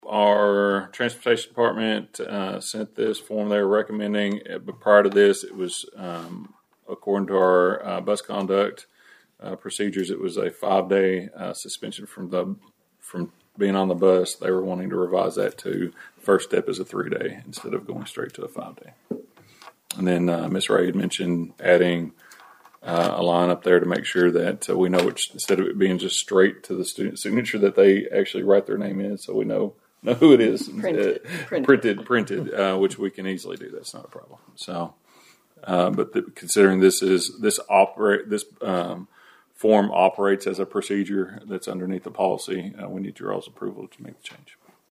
At Monday’s meeting, Superintendent Dr. Jeremy Roach explained that the School Bus Conduct Policy revision changes the length of suspension from the bus for a student’s first offense.